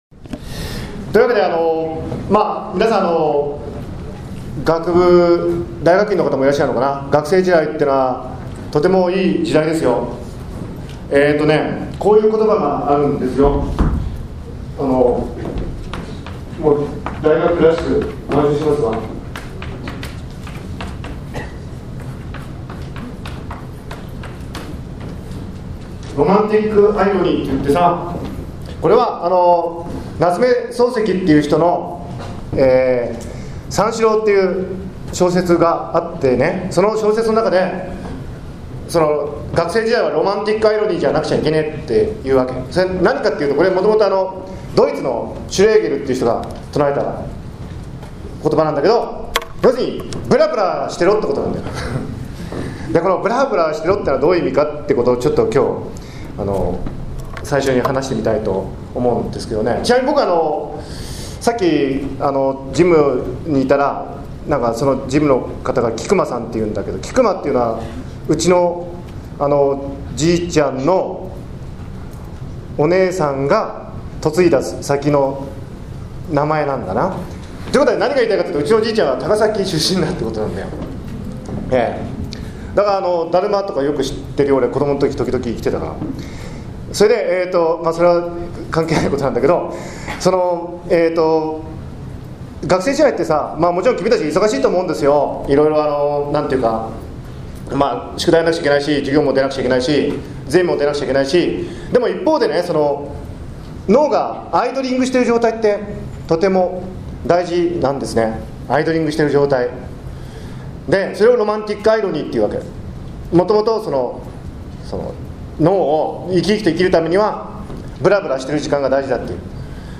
NHK前橋放送局、高崎経済大学 講演と質疑応答 ロマンティックアイロニー 夏目漱石 ぶらぶらすること